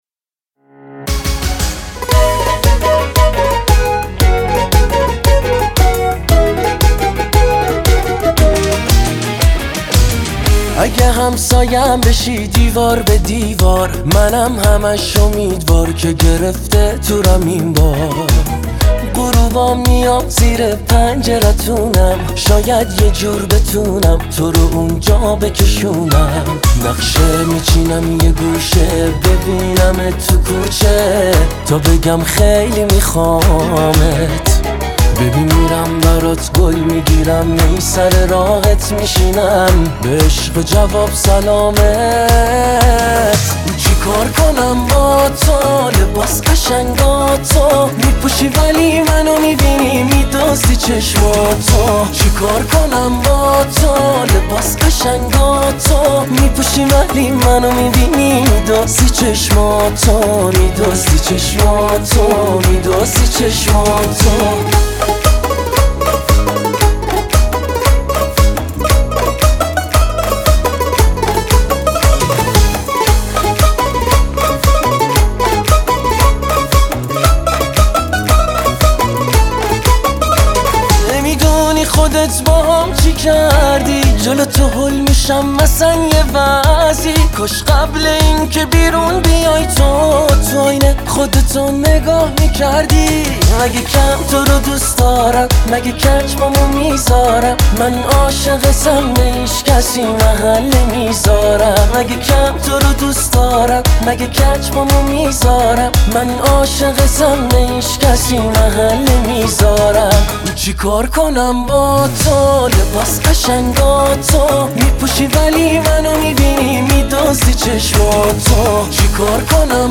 اهنگ شاد
اهنگ ایرانی و شاد